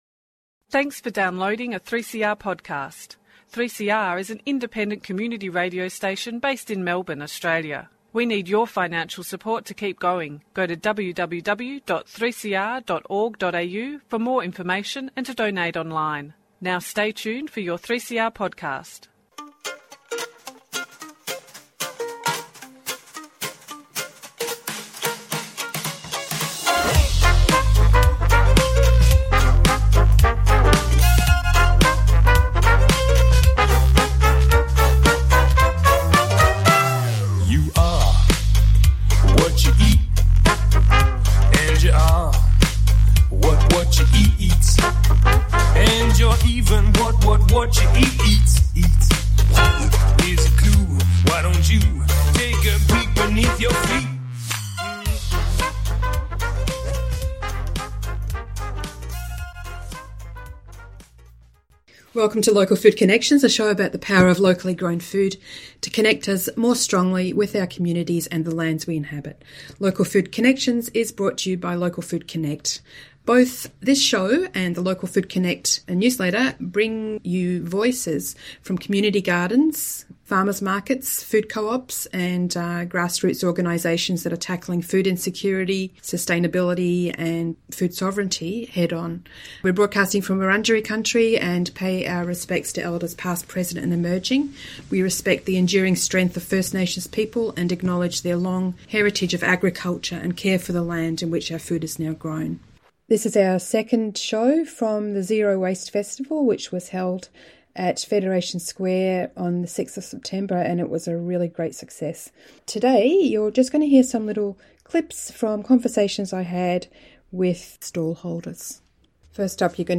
This episode features some more voices from the Zero Waste Festival 2025 that was held at Federation Square on 6 September.